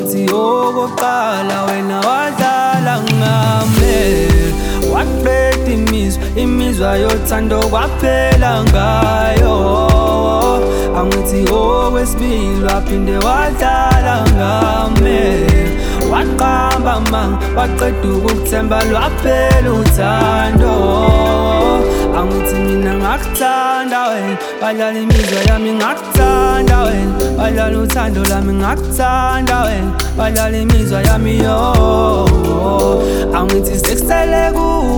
Afro-Pop
Жанр: Поп музыка